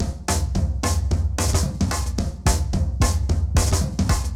RemixedDrums_110BPM_12.wav